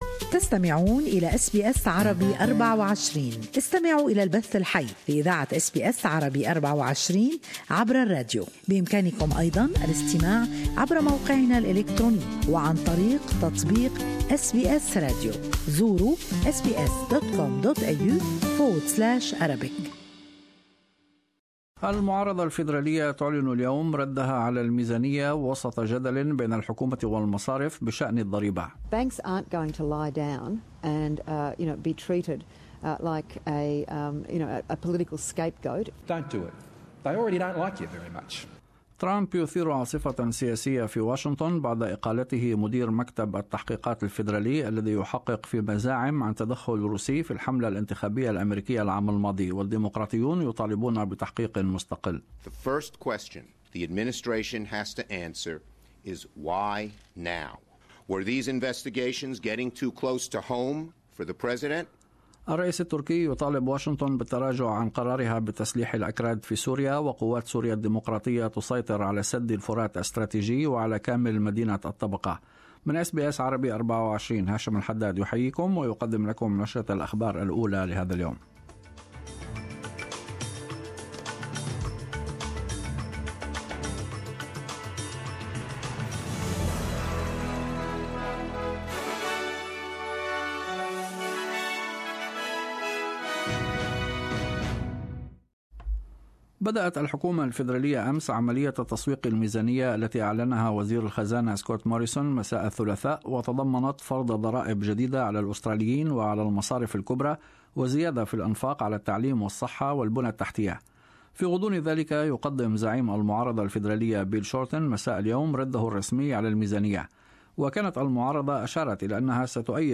Morning news bulletin with latest Australian and world news.